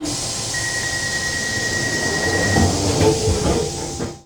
doorsclosed.ogg